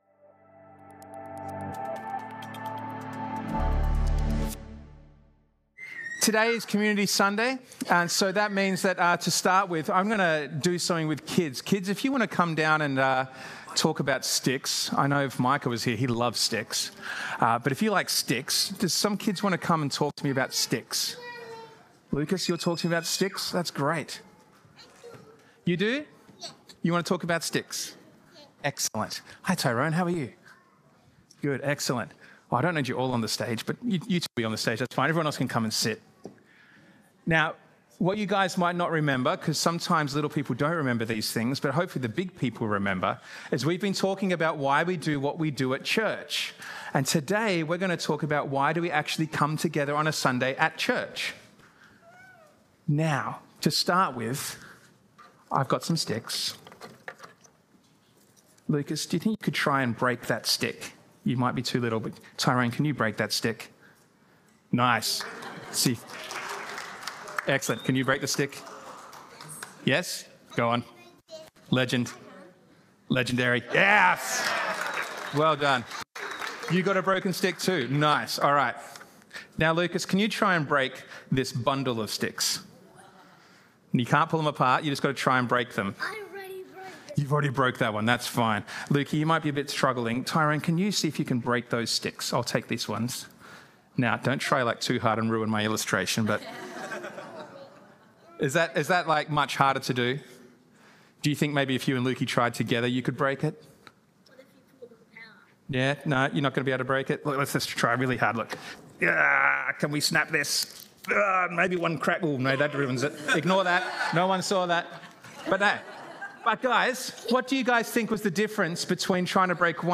Message: